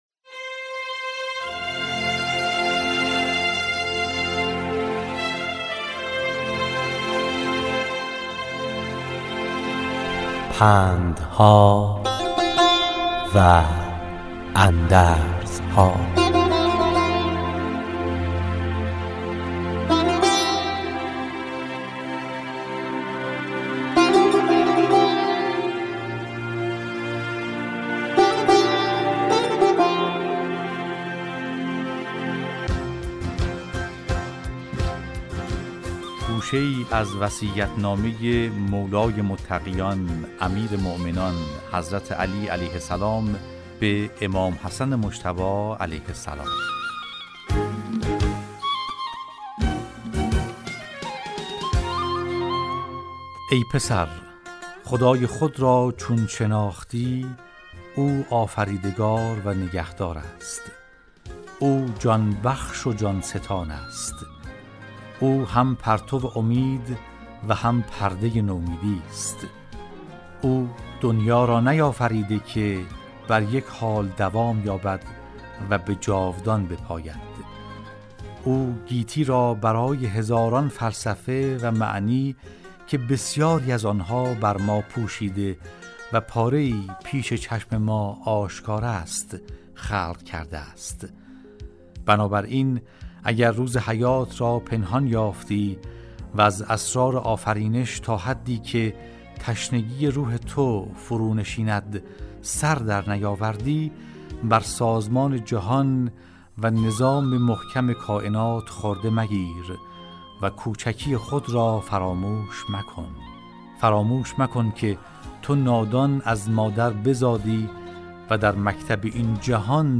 راوی برای شنوندگان عزیز صدای خراسان، حکایت های پندآموزی را روایت می کند